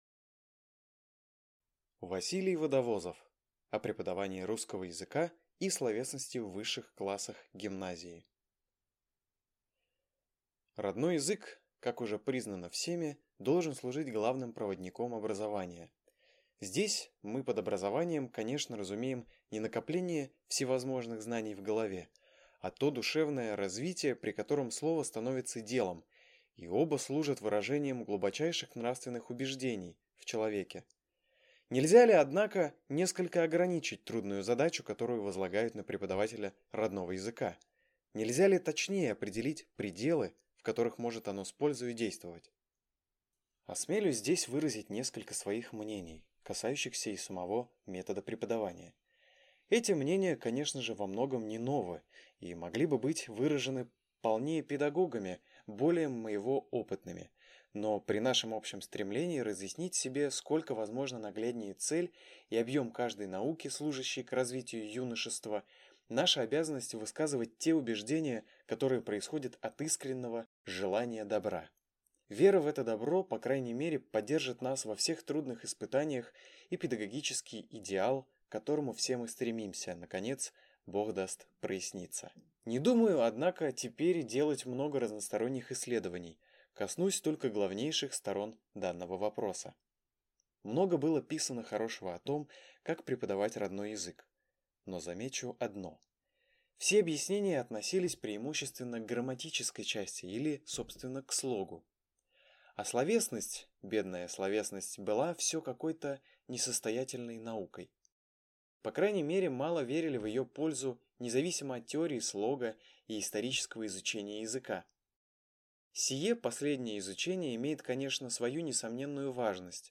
Аудиокнига О преподавании русского языка и словесности в высших классах гимназии | Библиотека аудиокниг